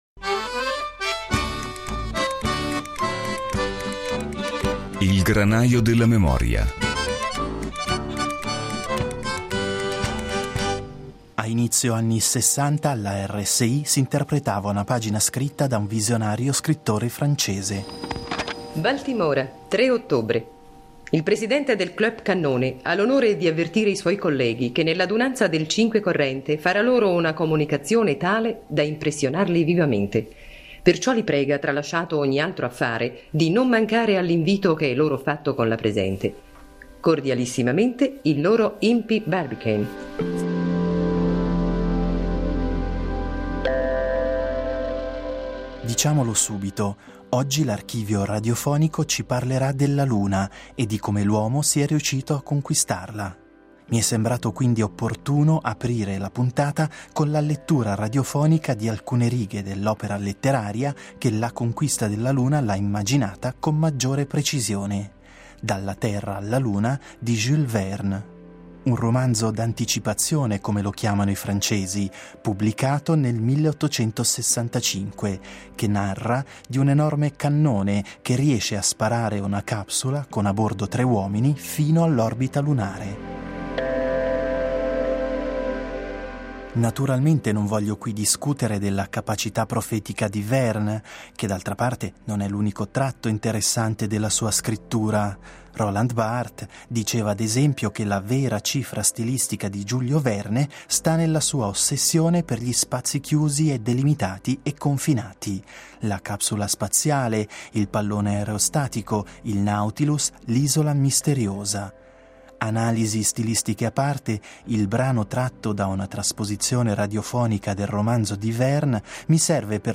L’archivio radiofonico ci racconterà allora di Apollo 11, dell’allunaggio e dell’impresa degli astronauti americani. Allo stesso tempo però, i frammenti tratti dai documenti sonori registrati a fine anni Sessanta, ci diranno del clima culturale che si respirava all’epoca, dei discorsi e ancora delle sensazioni provate di chi lo sbarco della luna lo ha vissuto qui da noi, con in piedi ben piantati per terra.